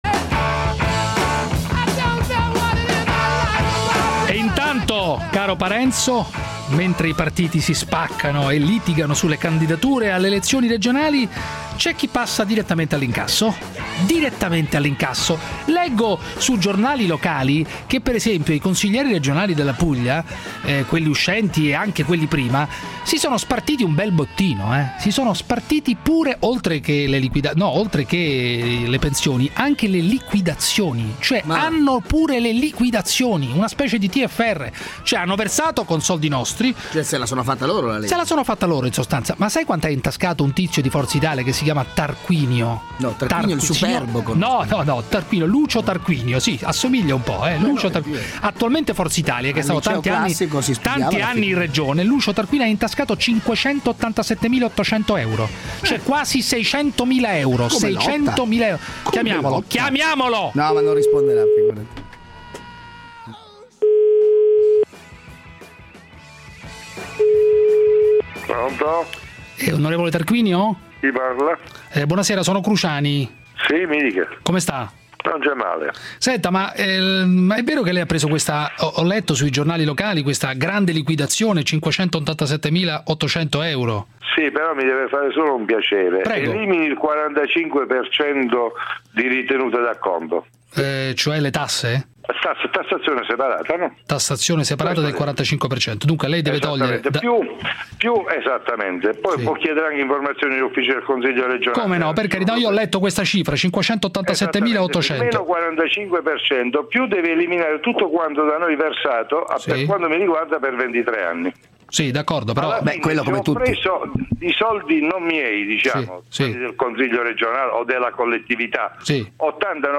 La nota trasmissione radiofonica condotta da Giuseppe Cruciani e Davide Parenzo ha chiamato il parlamentare foggiano Lucio Tarquinio, balzato alle cronache nazionali per la liquidazione da oltre 580 mila euro ricevuta dalla Regione Puglia alla fine del mandato.